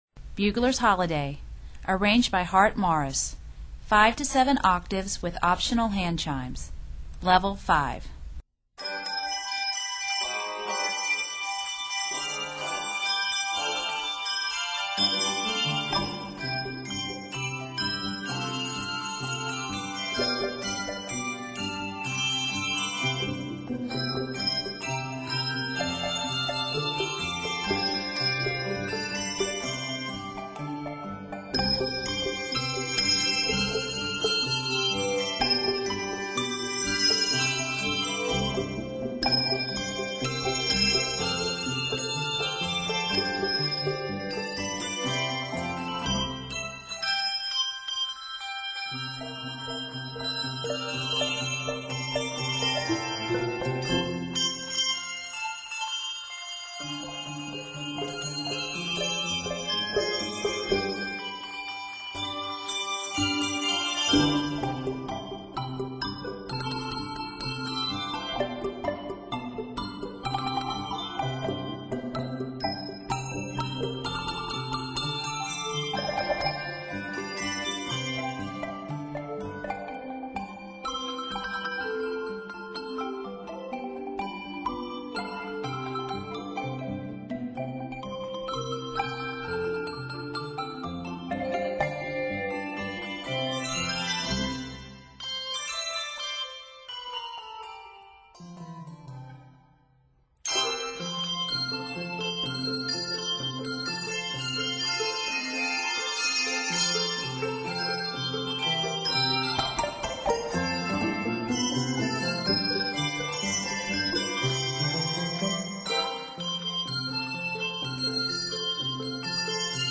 lite orchestral classic
bells